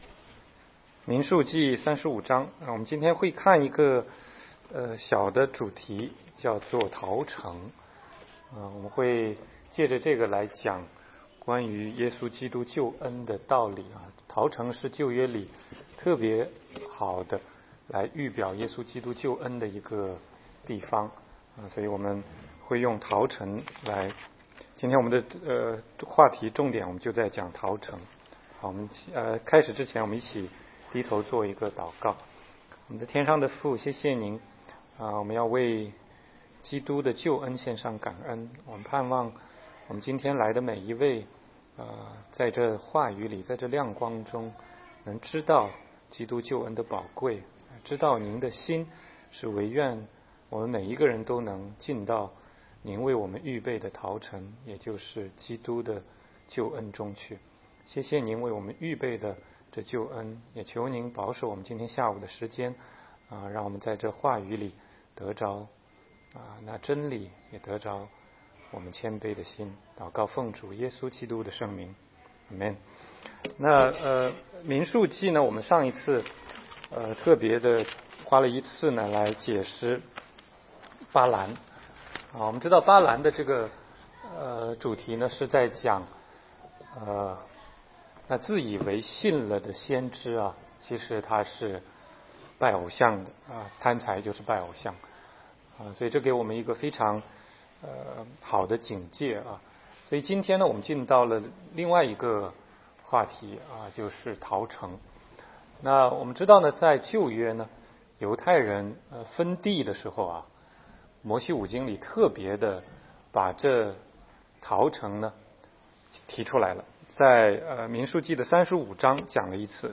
16街讲道录音 - 你在逃城里吗？